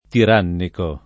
tirannico [ tir # nniko ]